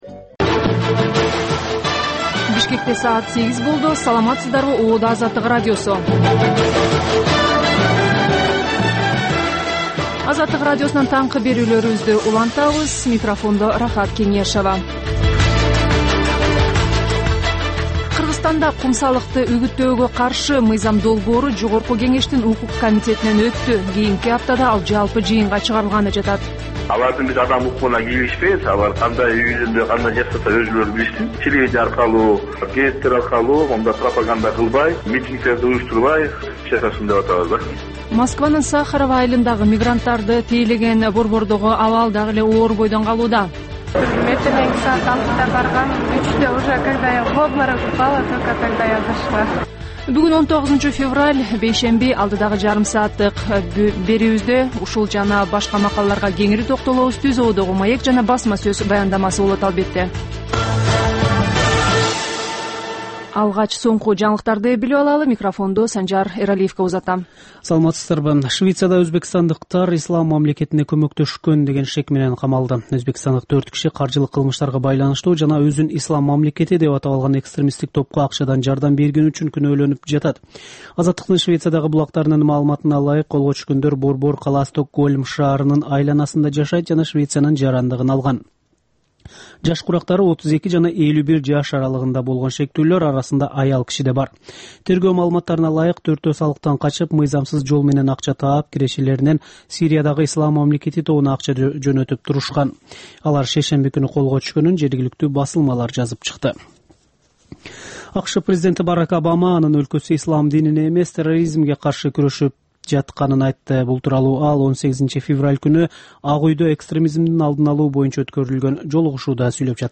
Бул таңкы үналгы берүү жергиликтүү жана эл аралык кабарлар, ар кыл орчун окуялар тууралуу репортаж, маек, талкуу, баян, күндөлүк басма сөзгө баяндама жана башка берүүлөрдөн турат. "Азаттык үналгысынын" бул таңкы берүүсү Бишкек убакыты боюнча саат 08:00ден 08:30га чейин обого чыгарылат.